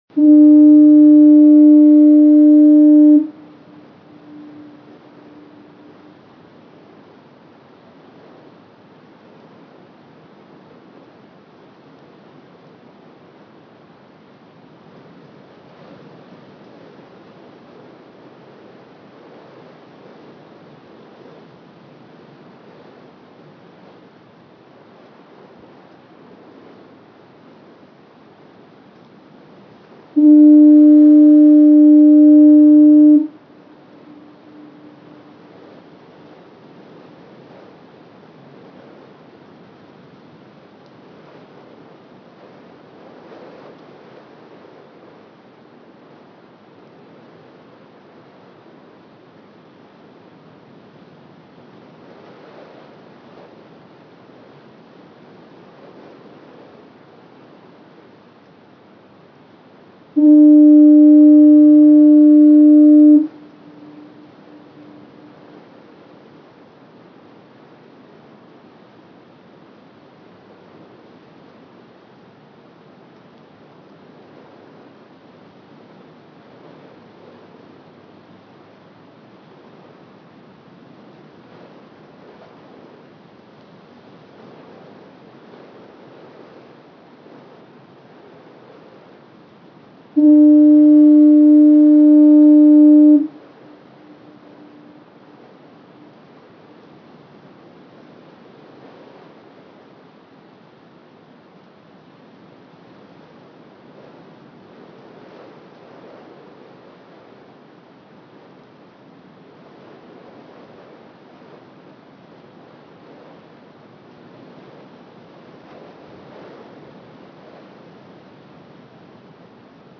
Звуки маяка
Сигнальный горн маяка предупреждающий в тумане